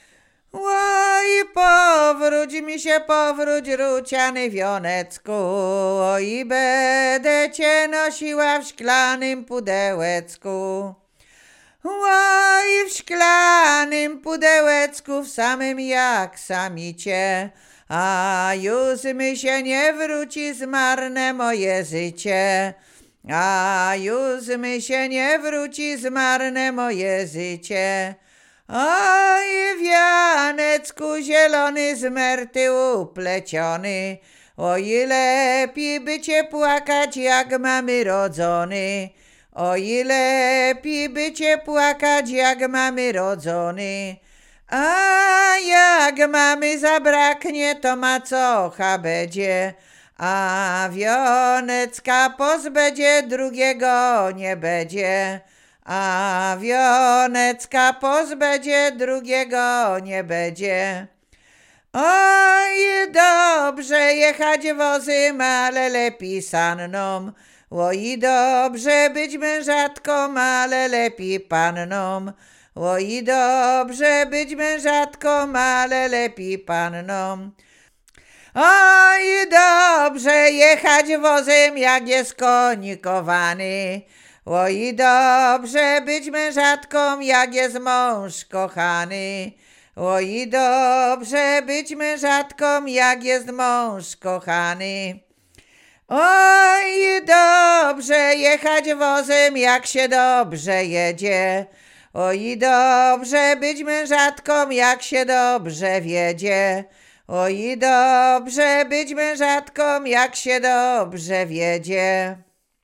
Łowickie
Przyśpiewki
miłosne przyśpiewki weselne wesele